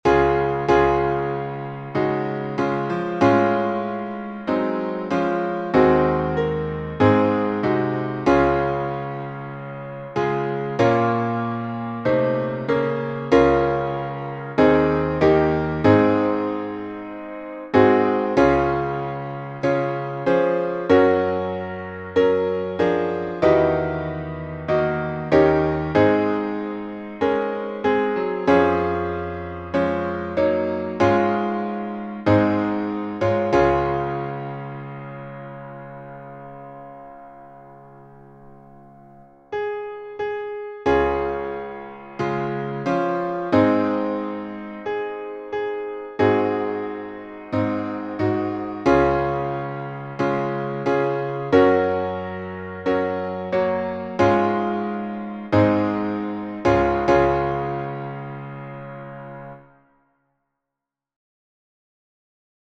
Key signature: D flat major (5 flats) Time signature: 4/4